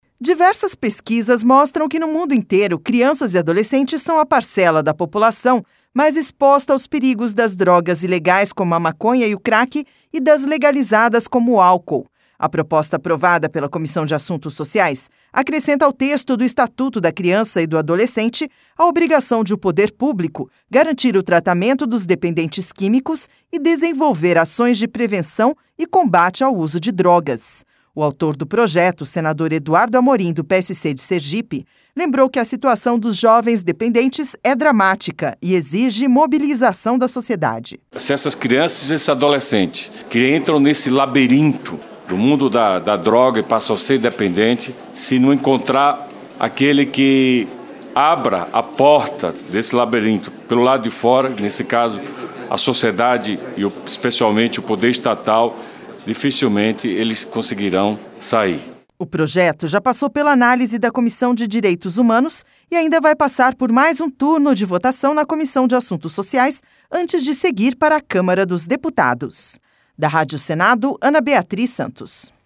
A proposta aprovada pela Comissão de Assuntos Sociais acrescenta ao texto do Estatuto da Criança e do Adolescente a obrigação de o poder público garantir o tratamento dos dependentes químicos e desenvolver ações de prevenção e combate ao uso de drogas. O autor do projeto, senador Eduardo Amorim, do PSC de Sergipe, lembrou que a situação dos jovens dependentes é dramática e exige mobilização da sociedade.